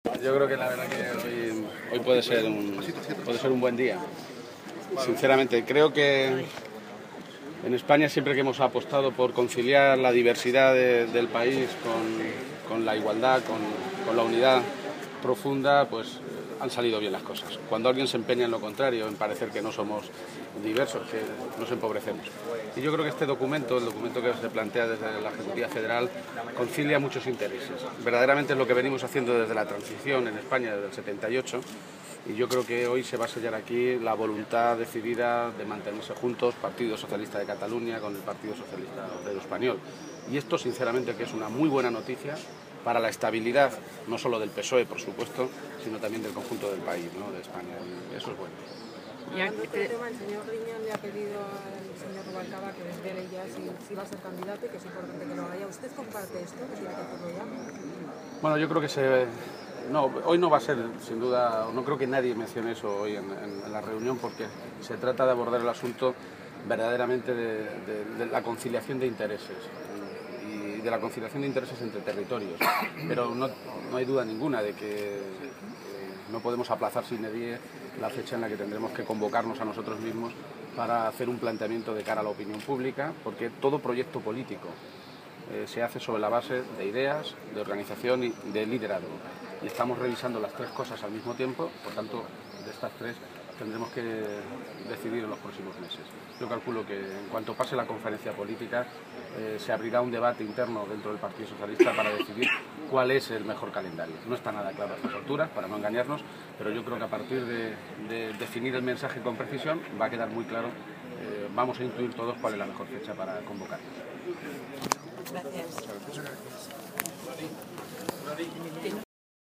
García-Page en el Consejo Territorial del PSOE
Cortes de audio de la rueda de prensa